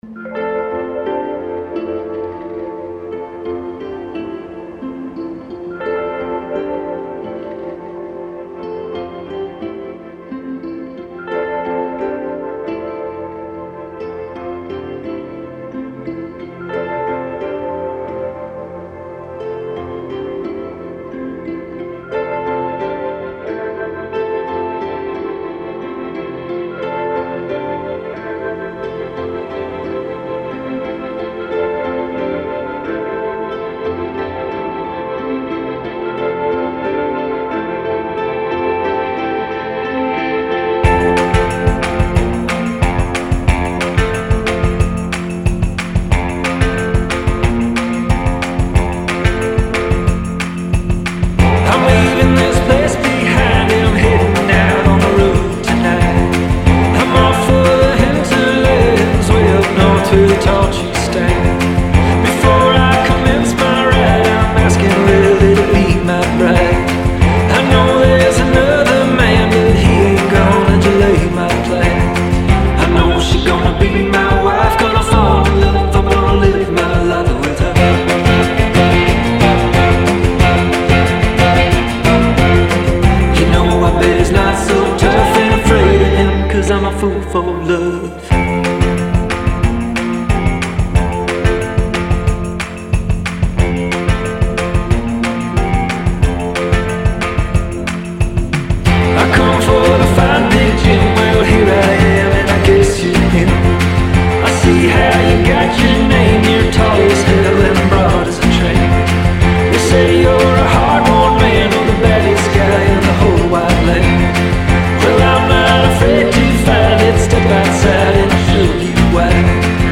folk rock band